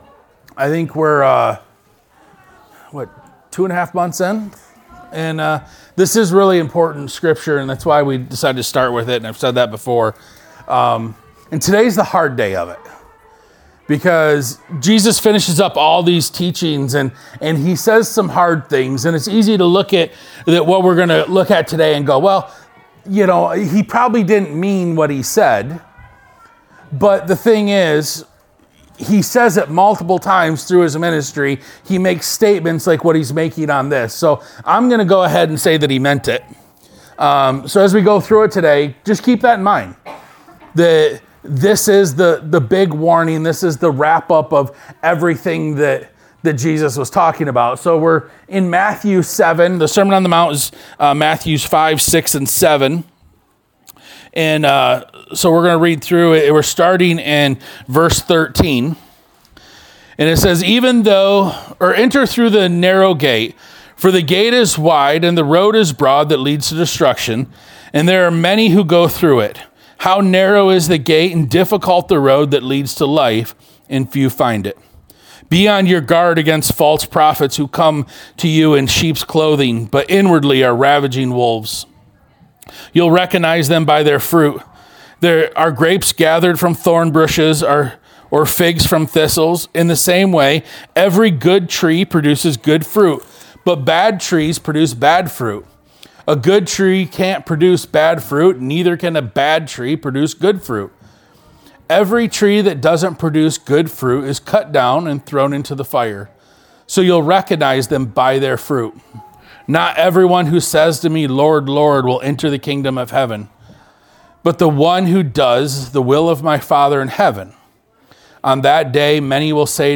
Sermon 11-23.mp3